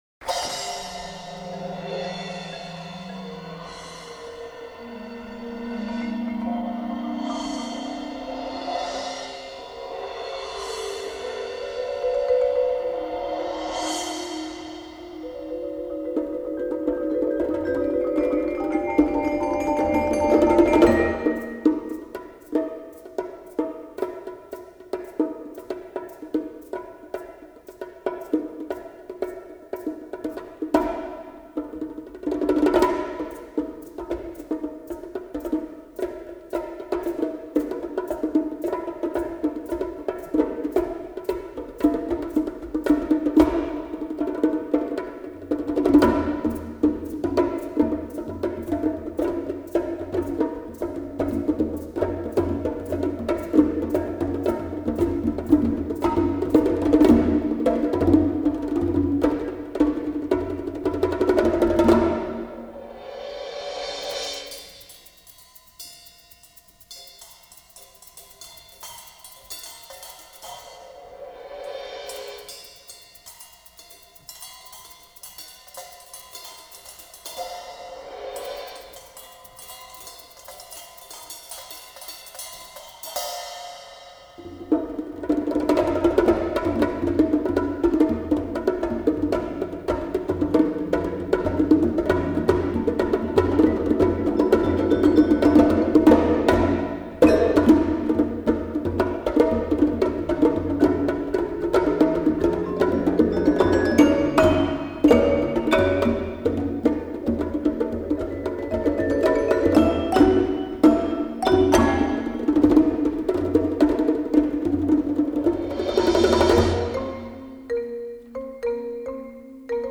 Genre: Percussion Ensemble
# of Players: 6
Percussion 1 (marimba)
Percussion 2 (marimba (5 octaves), shaker)
Percussion 3 (vibraphone, suspended cymbal)